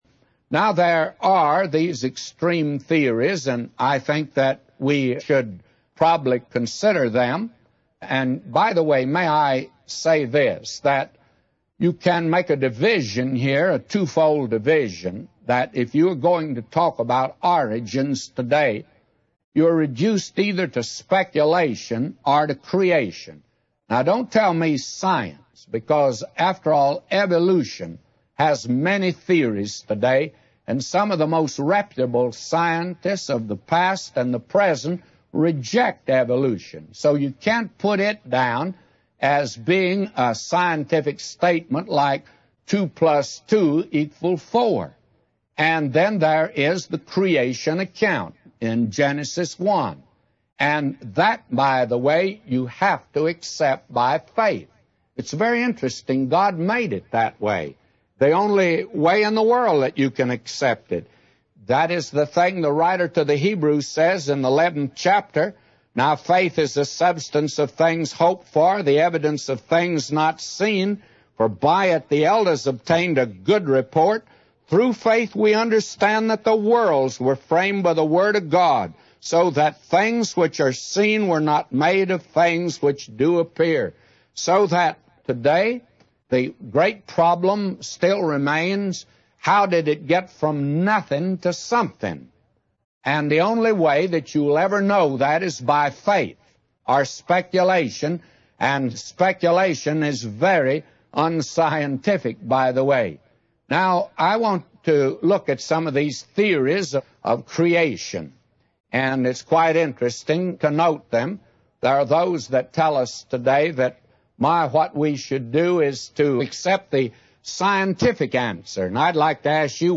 In this sermon, the speaker discusses various theories proposed by man regarding the origin of the universe. He emphasizes that the Bible was not written for learned professors but for simple people of every age and land.